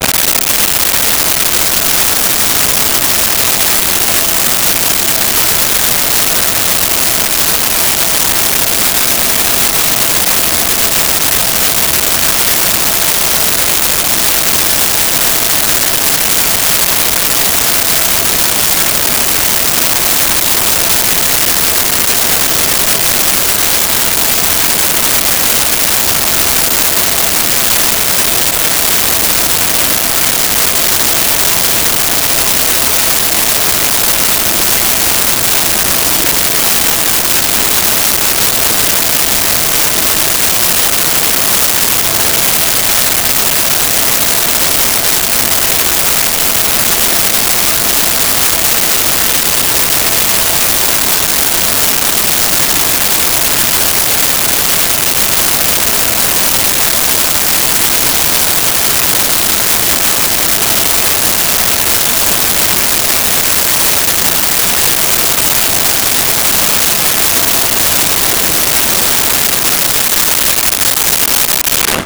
Sea Helicopter Idle
Sea Helicopter Idle.wav